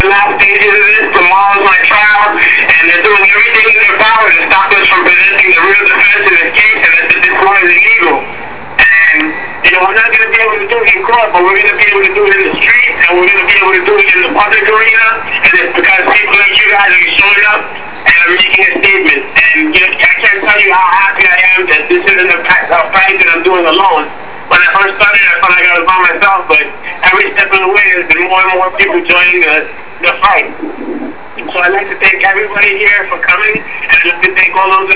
Holding Phone To Mic)
Speaking Over The Phone
Speaking Via Cellphone